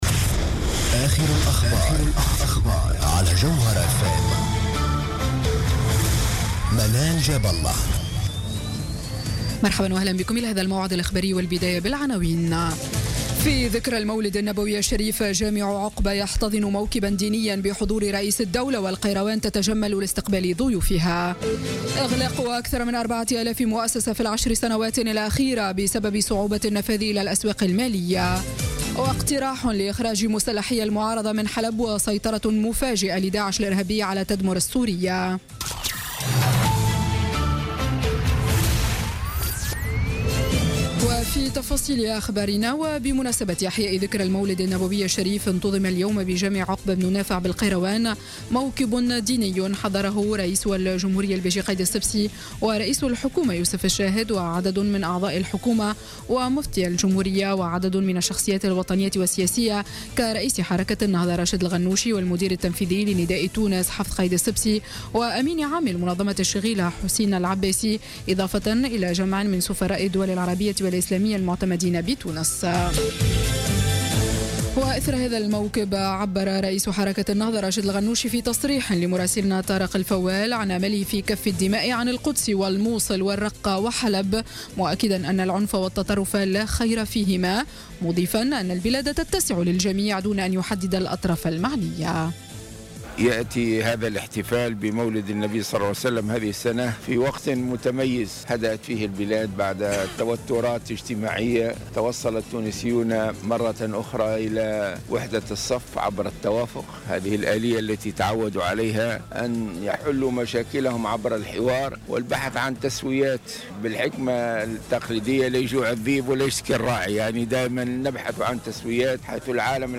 Journal Info 19h00 du Dimanche 11 Décembre 2016